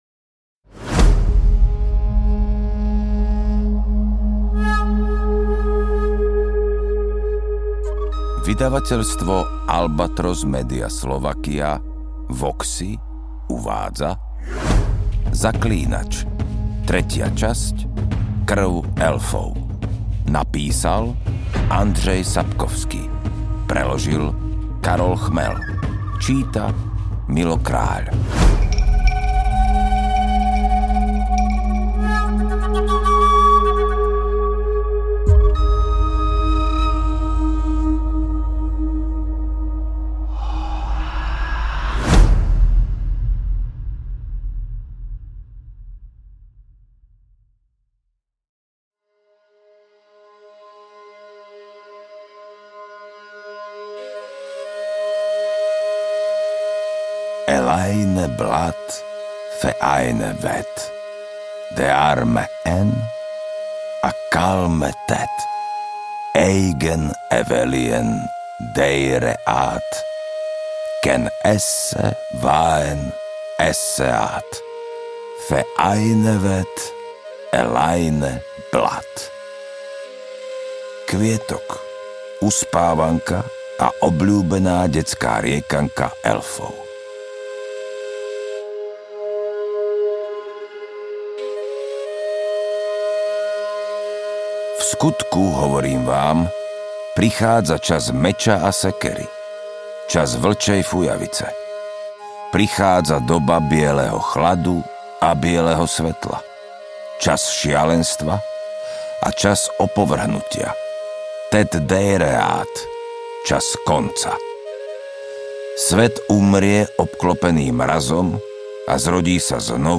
AudioKniha ke stažení, 60 x mp3, délka 13 hod. 49 min., velikost 806,5 MB, slovensky